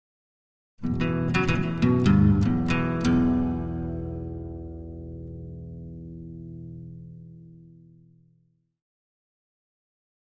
Acoustic Melody Version 2 - Lower Part